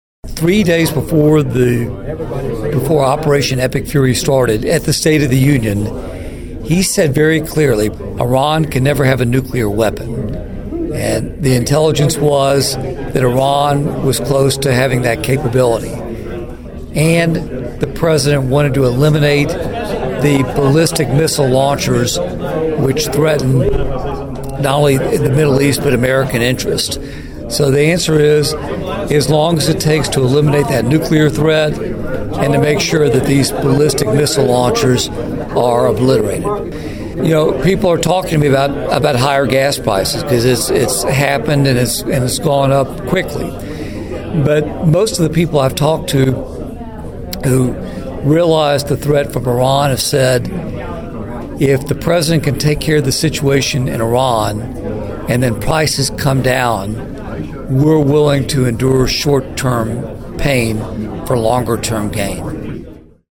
Congressman Kustoff was also asked about the possible duration of the Iran conflict, and the rising costs of fuel.(AUDIO)